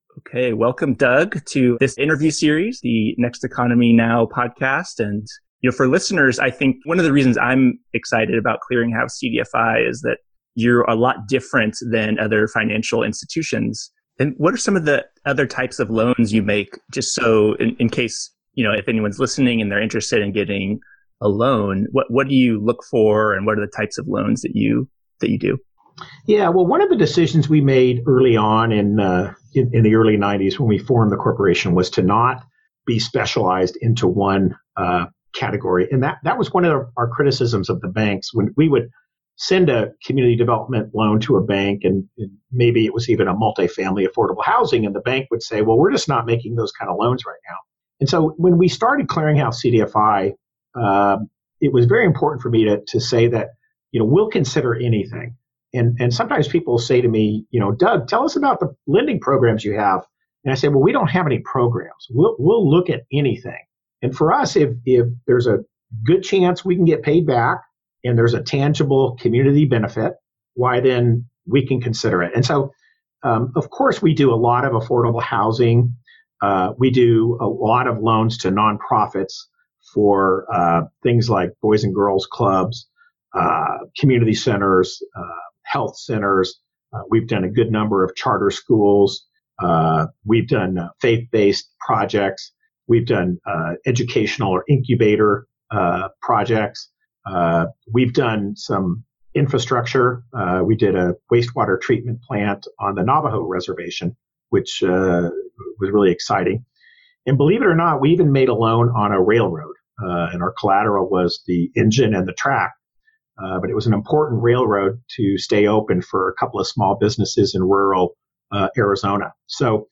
CCDFI Interview Series